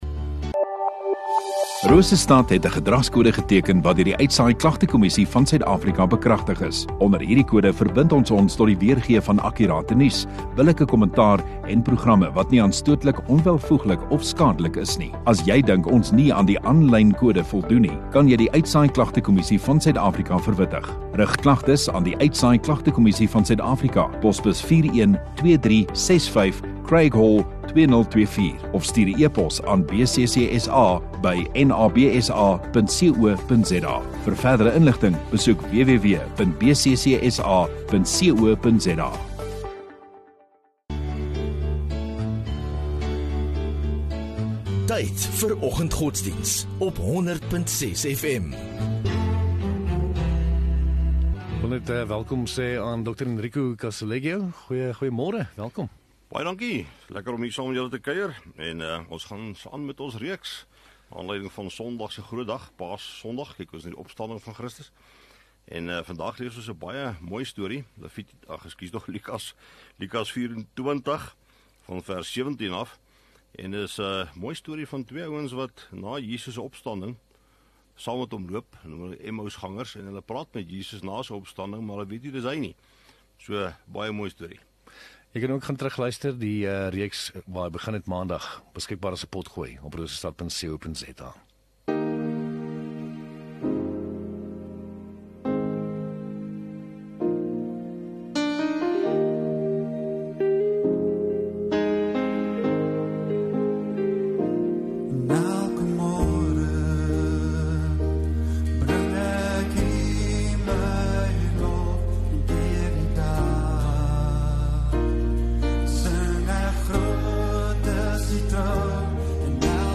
4 Apr Donderdag Oggenddiens